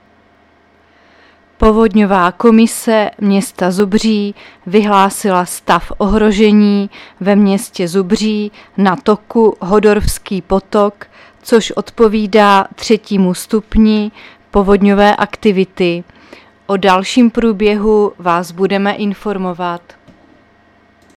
Záznam hlášení místního rozhlasu 14.9.2024